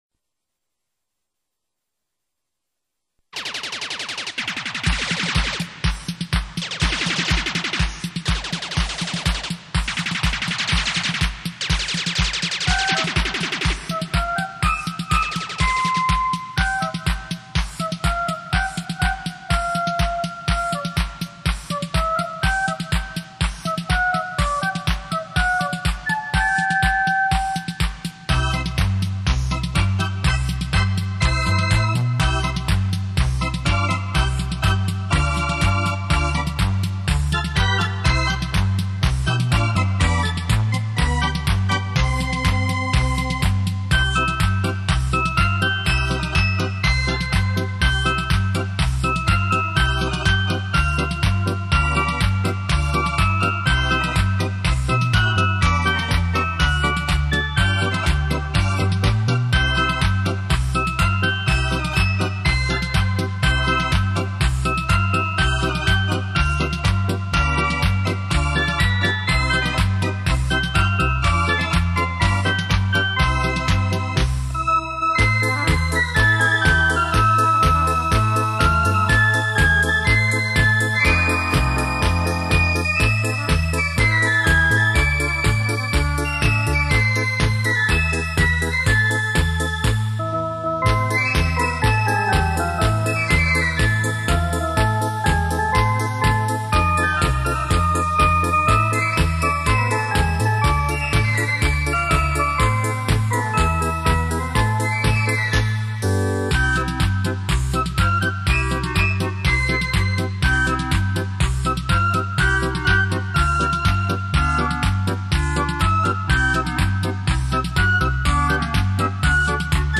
音效一流
奔放电子琴